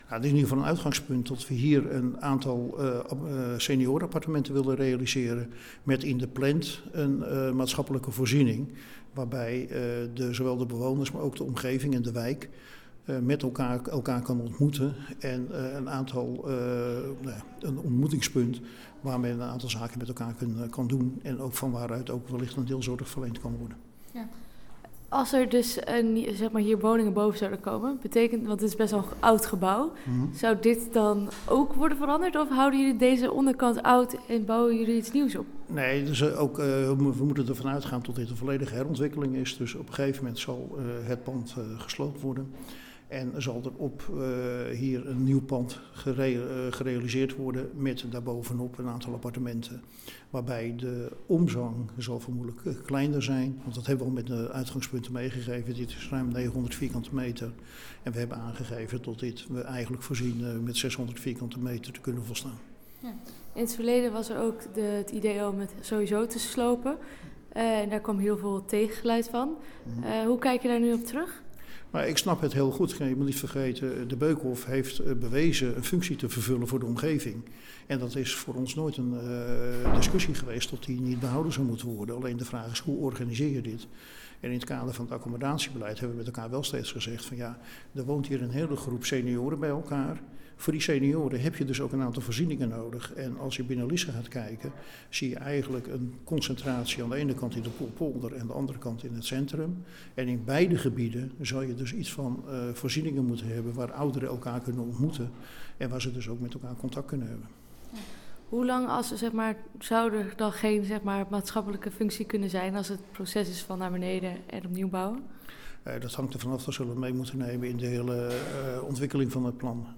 De ondertekening vond plaats bij De Beukenhof.
Interview met wethouder Kees van der Zwet